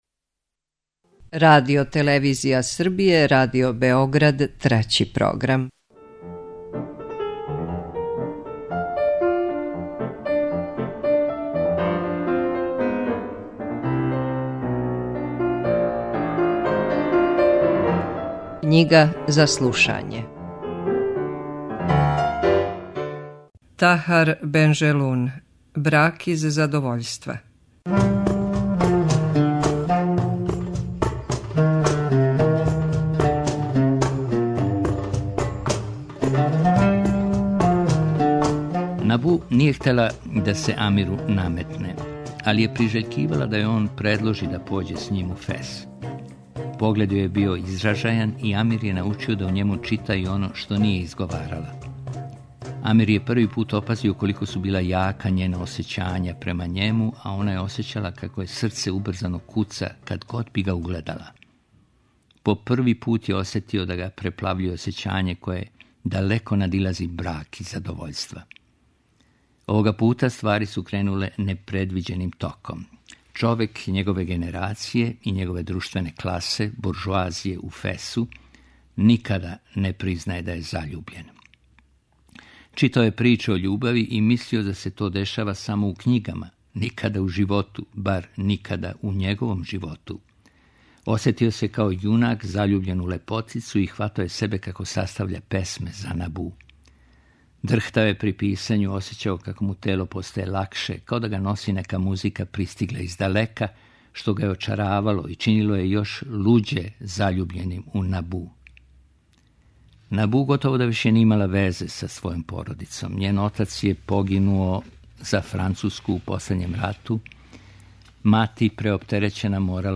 Knjiga za slušanje